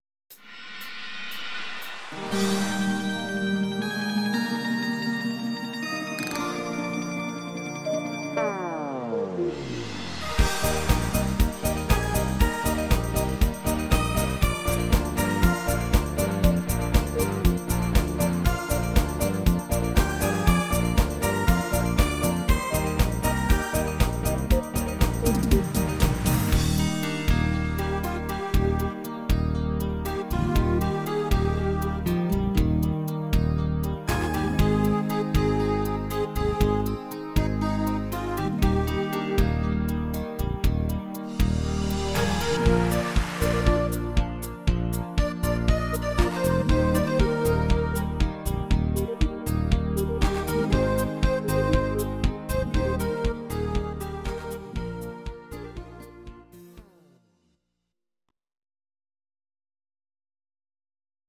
Easy to sing -3